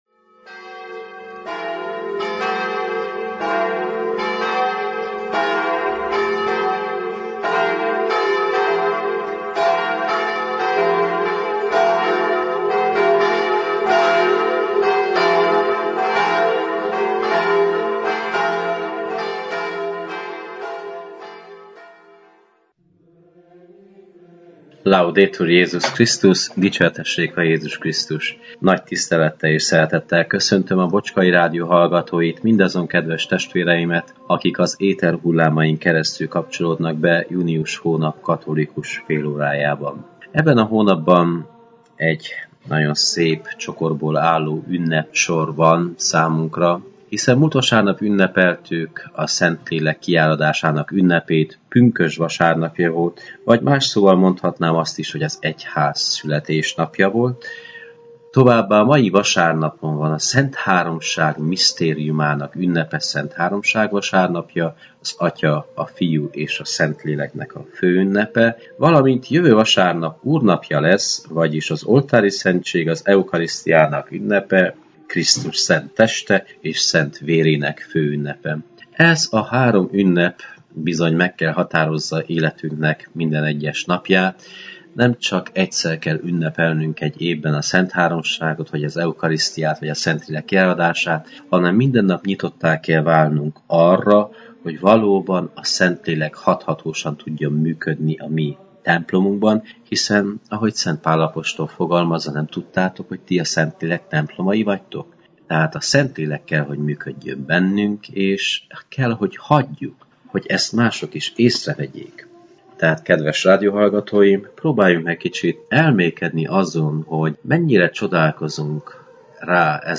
Igét hirdet
a clevelandi Szent Imre Katolikus Templomból.